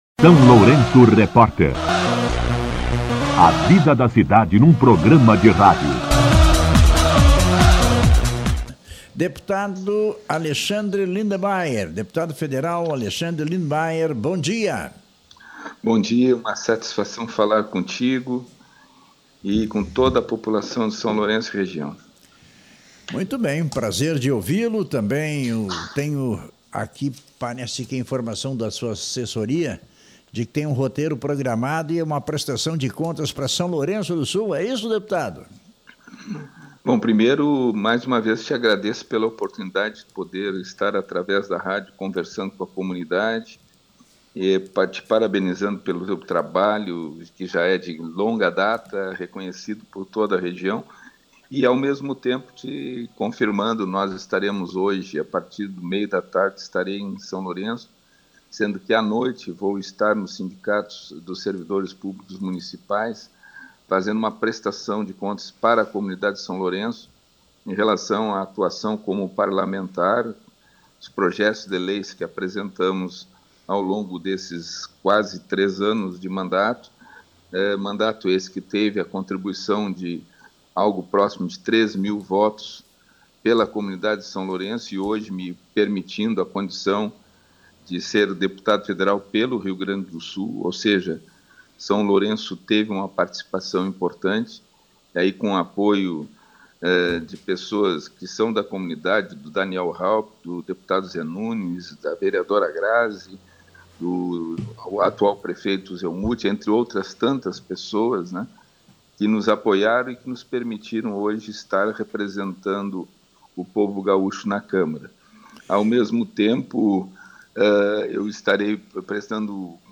Entrevista com o deputado federal Alexandre Lindenmeyer